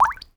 water_drop_drip_single_15.wav